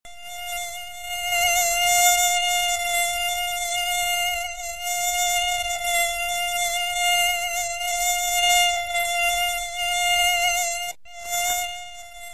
. SMS hangok .
Mosquito_2.mp3